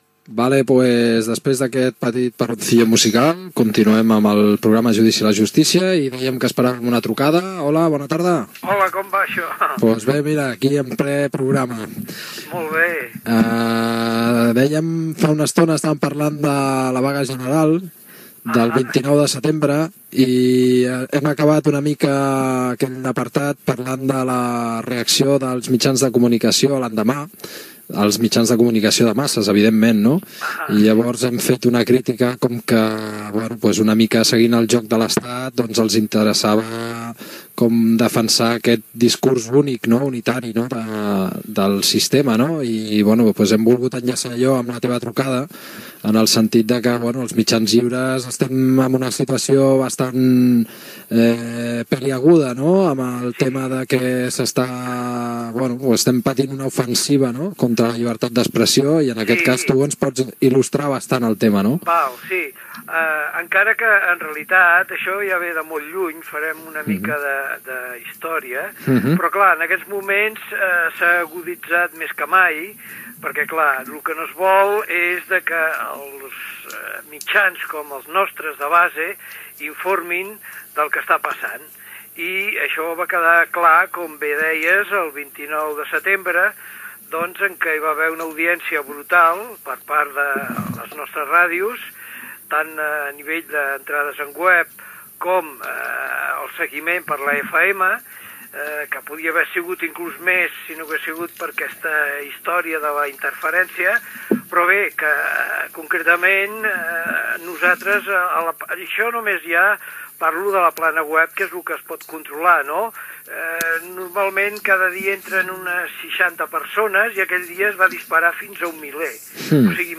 Entrevista
la manca de regulació de les ràdios lliures catalanes, la seva història i per explicar què passa amb les interferencies que pateixen Radio Pica i Radio Bronka per part de Mola FM al dial 96.6 de l'FM Gènere radiofònic Informatiu